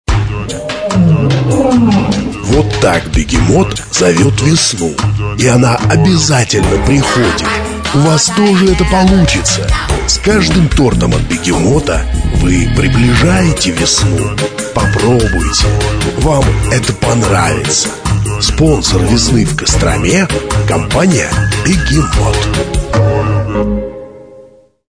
Рекламные радио ролики записаны в формате mp3 (64 Kbps/FM Radio Quality Audio).